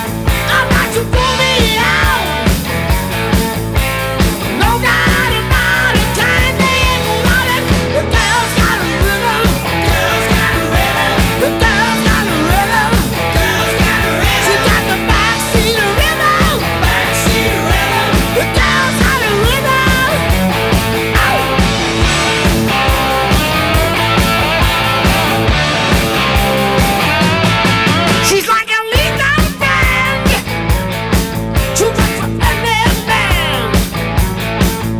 Жанр: Пост-хардкор / Хард-рок / Рок
# Hard Rock